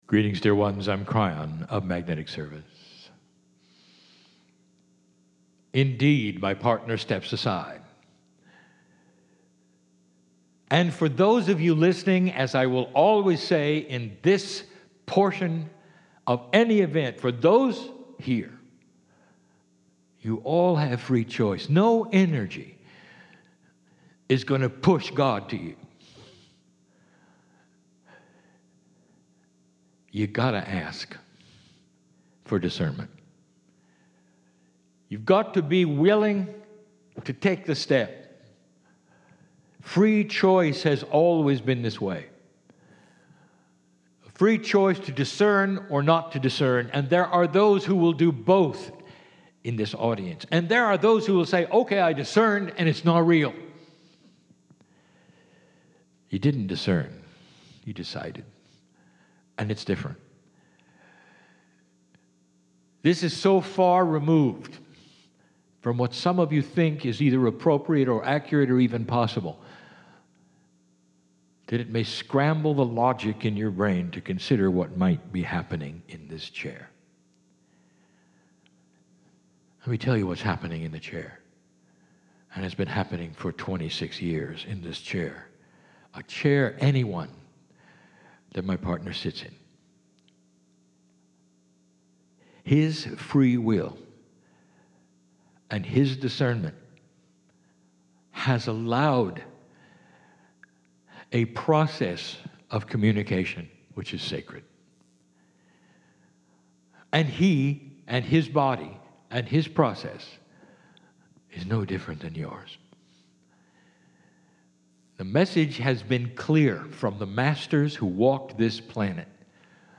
Live Kryon Channelling